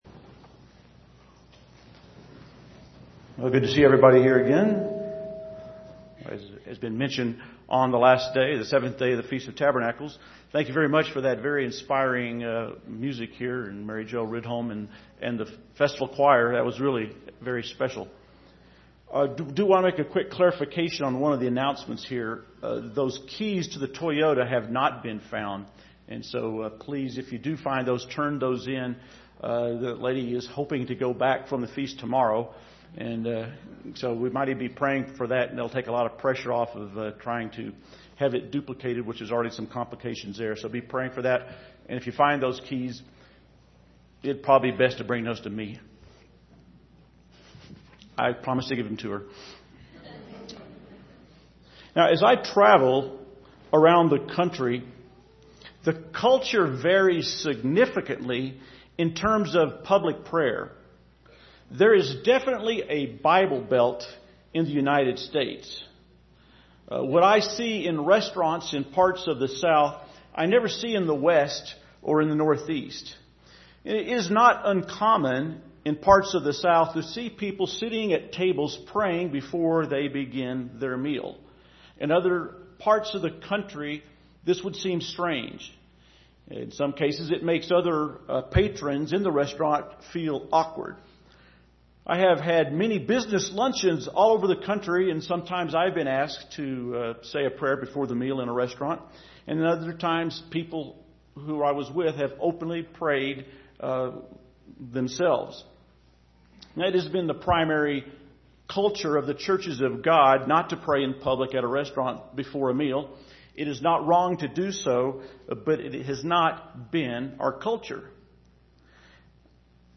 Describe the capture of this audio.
This sermon was given at the Steamboat Springs, Colorado 2014 Feast site.